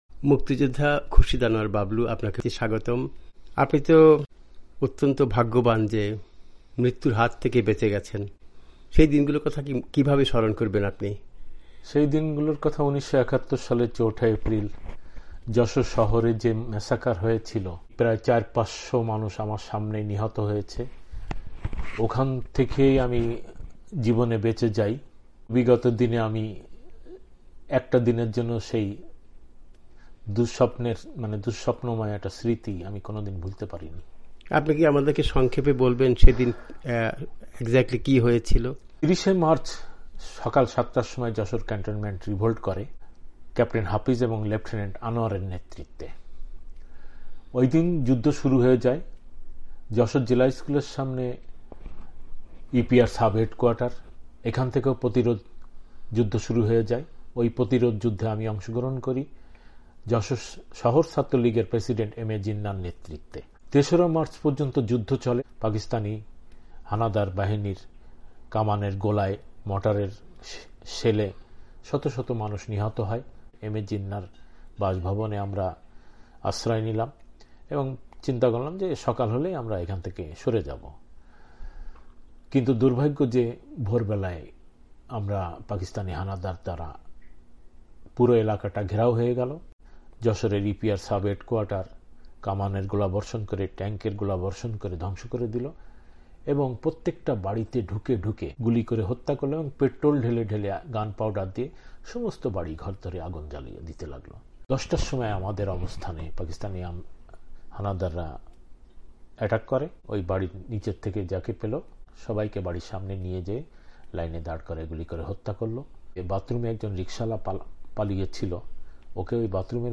এক বিশেষ সাক্ষাতকার